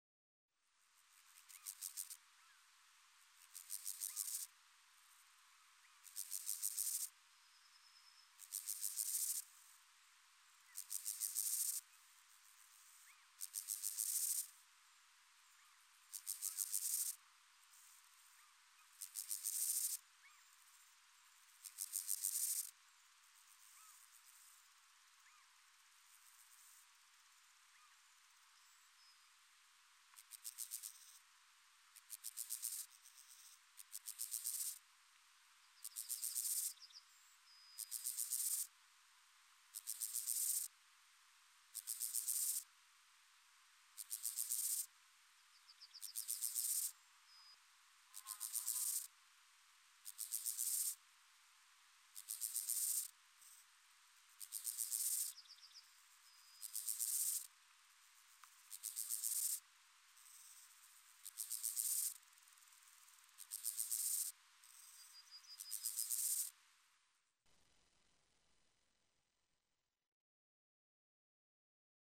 Stor enggræshoppe (Chorthippus dorsatus)
Lyt til den store enggræshoppe.
stor-enggræshoppe.mp3